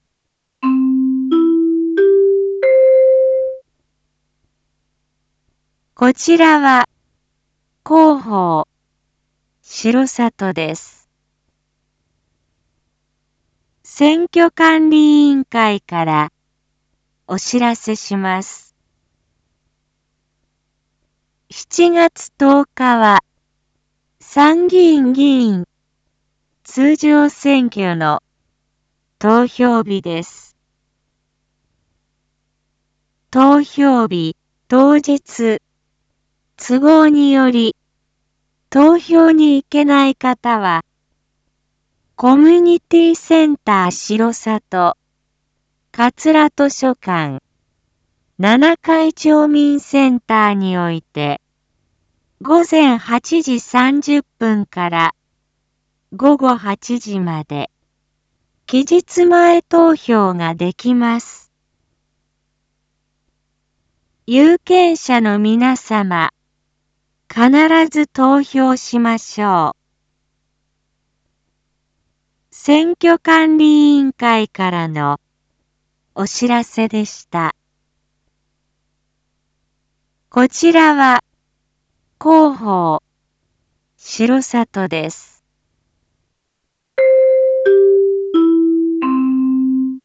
一般放送情報
Back Home 一般放送情報 音声放送 再生 一般放送情報 登録日時：2022-07-03 19:01:36 タイトル：参議院議員通常選挙（全地区期日前投票について） インフォメーション：こちらは広報しろさとです。